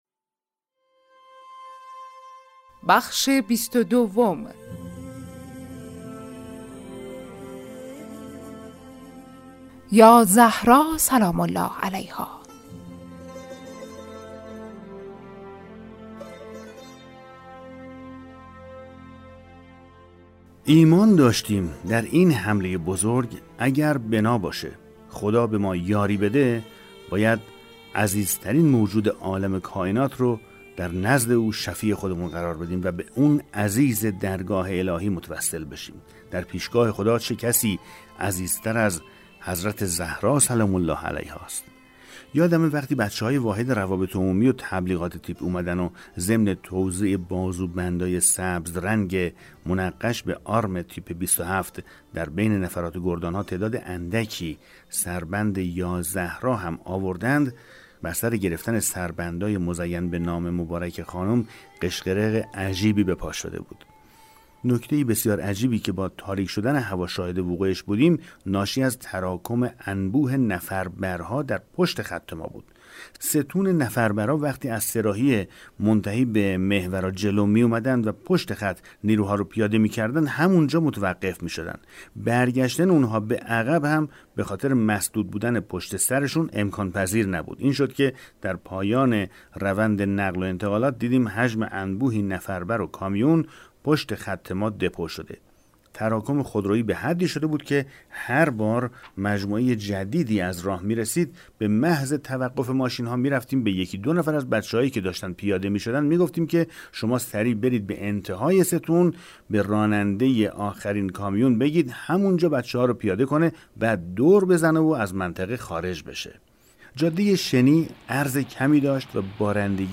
بخش‌هایی از این کتاب ارزشمند را در همدان صداگذاری کرده اند.